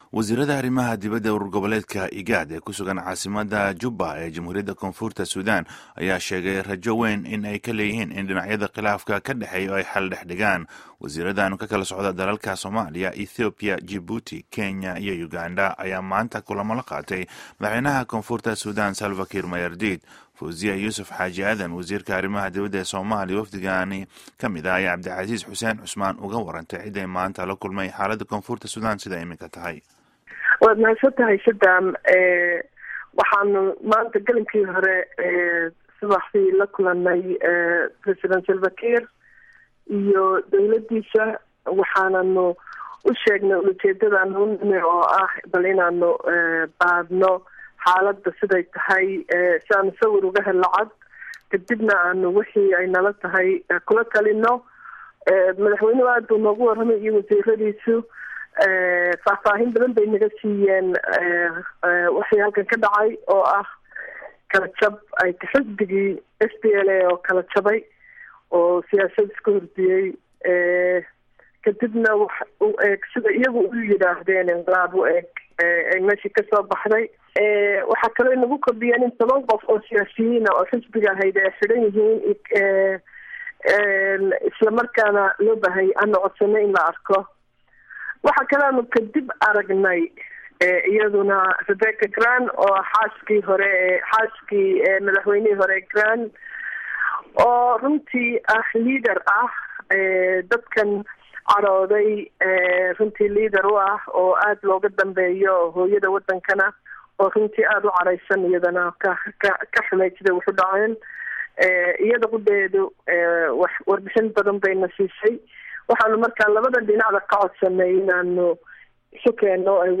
Waraysiga Wasiirka Arrimaha Dibadda